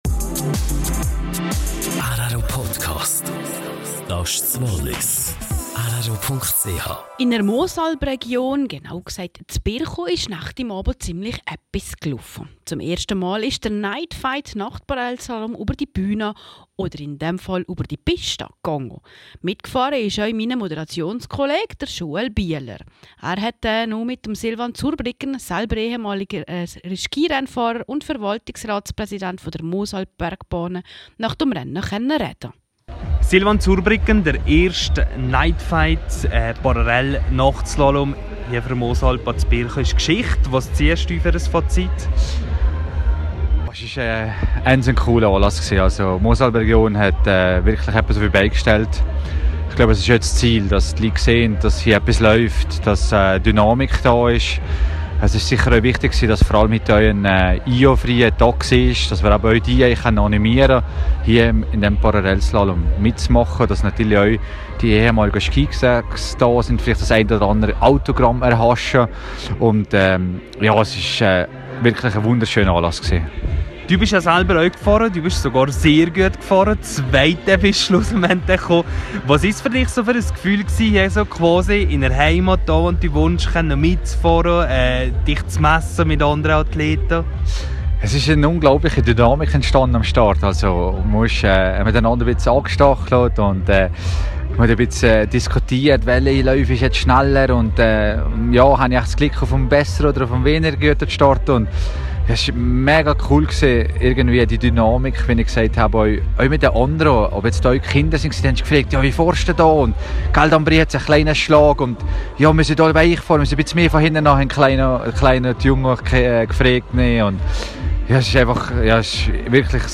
zeigte sich nach dem Anlass im rro-Interview sehr zufrieden